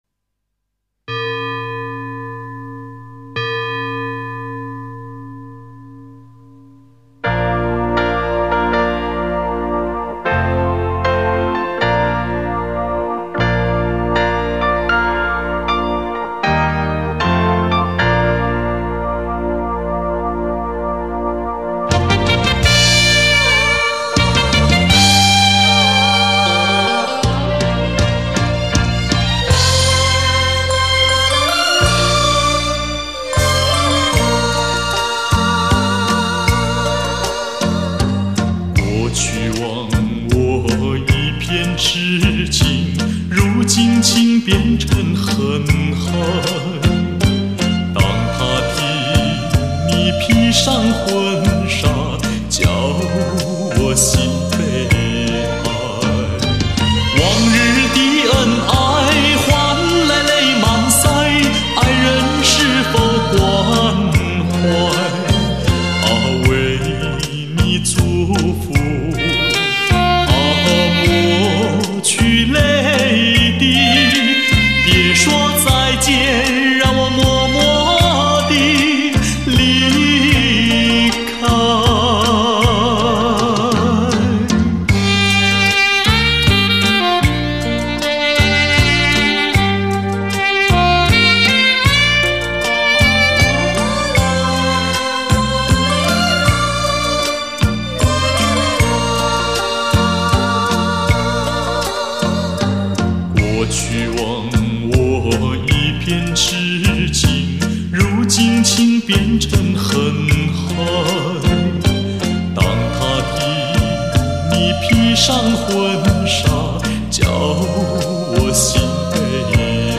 磁带版试听曲：